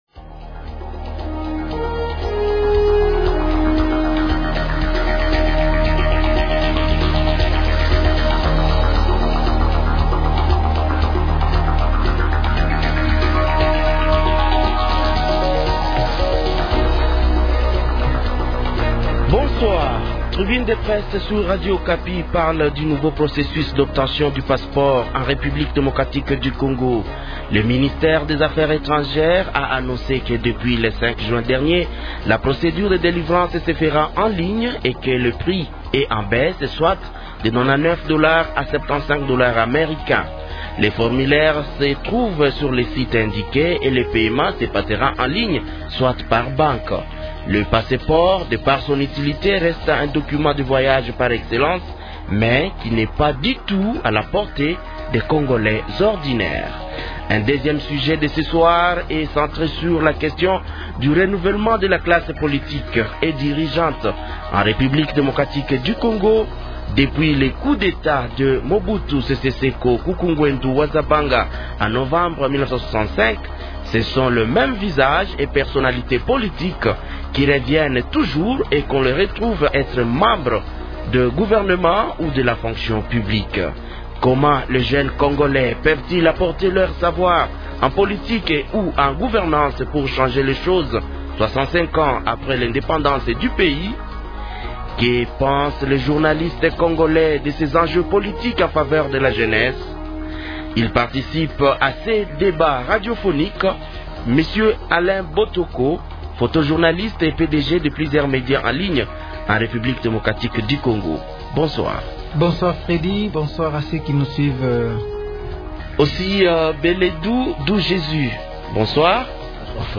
Pour en parler, trois invités :